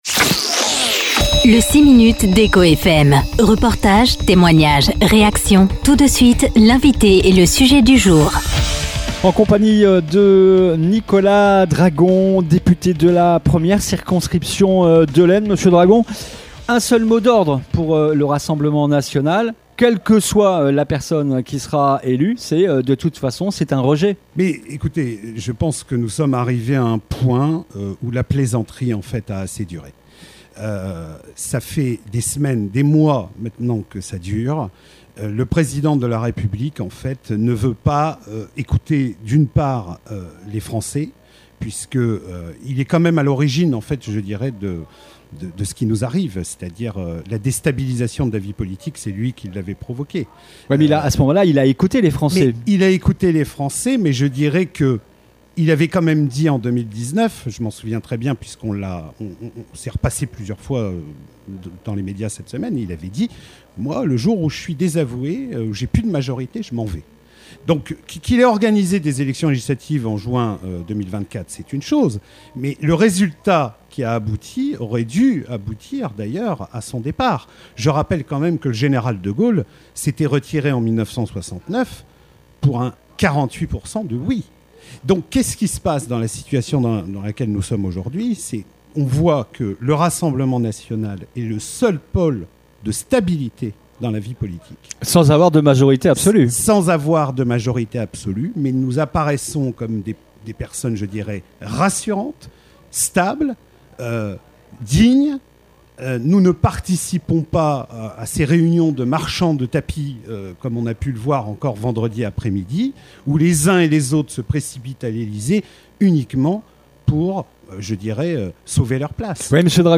Ce dimanche 12 octobre, le député de la 1ere circonscription de l’Aisne était l’invité de la rédaction d’Echo FM pour un 6 minutes exceptionnel en direct de la 41e édition de la fête de la Pomme et du Cidre.